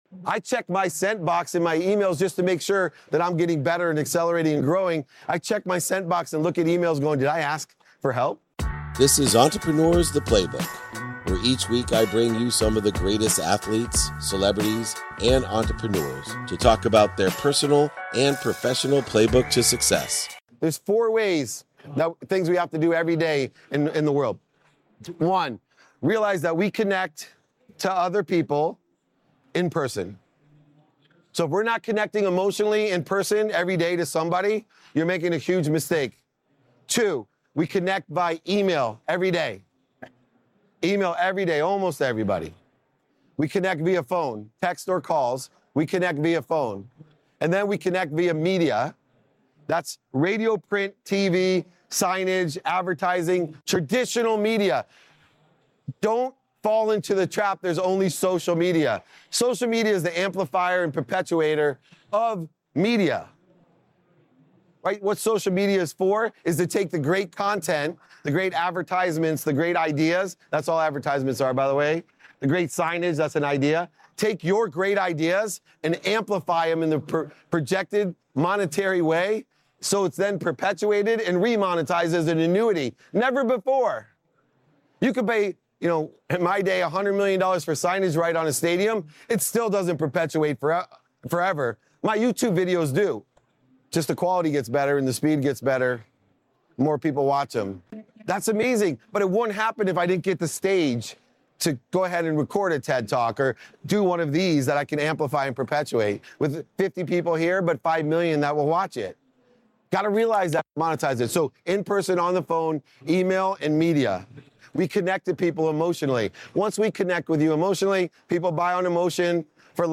In today's episode, I'm taking you back to a round table I hosted at the 2018 RISE Conference in Hong Kong. I delve into the importance of nurturing relationships, both through modern social media platforms and timeless methods like the phone, email, and in person. I also share insights on ramping up productivity: the power of fostering connections, the necessity of prioritizing health, and the effectiveness of a well-organized calendar.